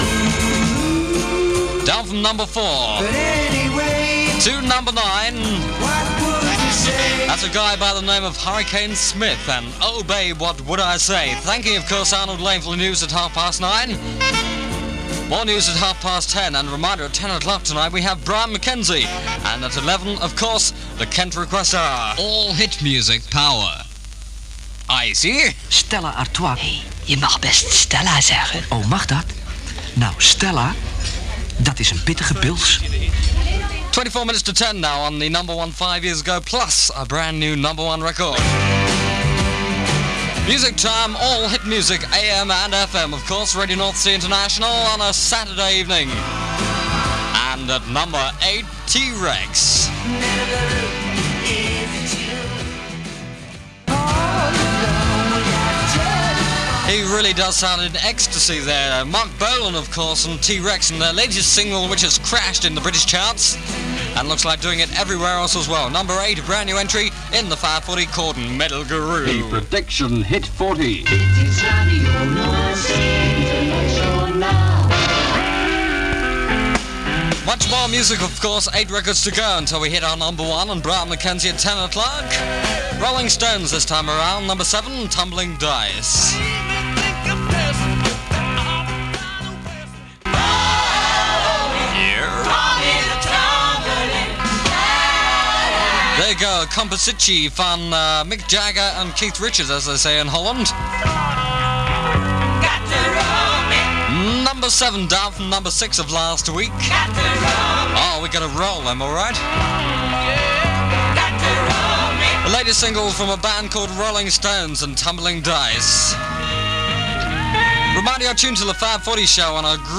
recorded from the station's FM outlet.